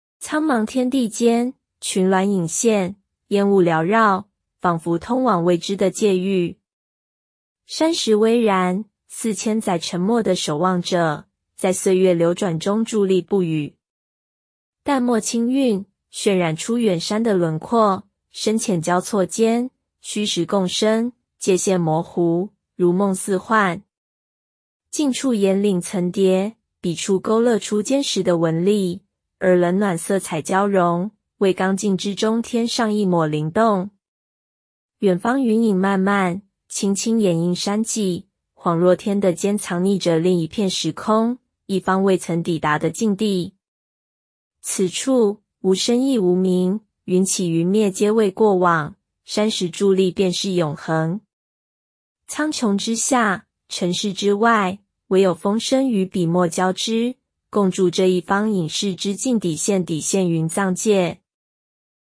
中文語音導覽